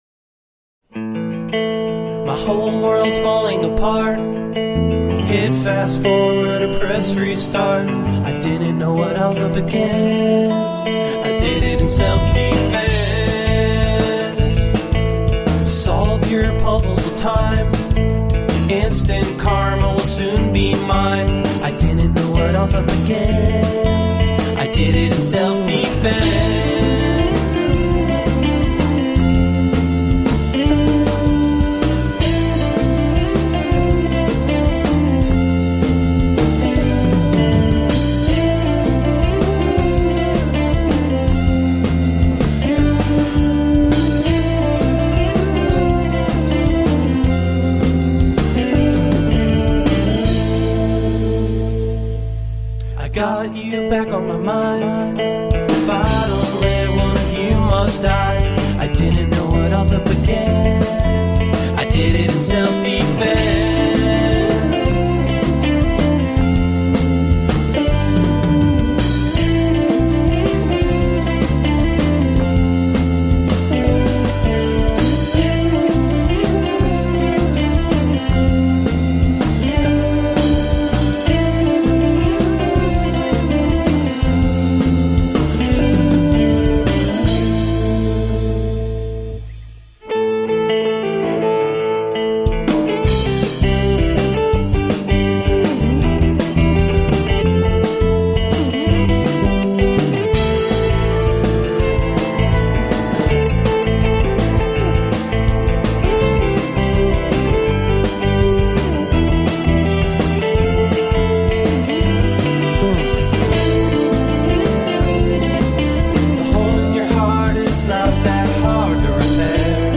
A catchy blend of pop/folk/electro.
Tagged as: Alt Rock, Electro Rock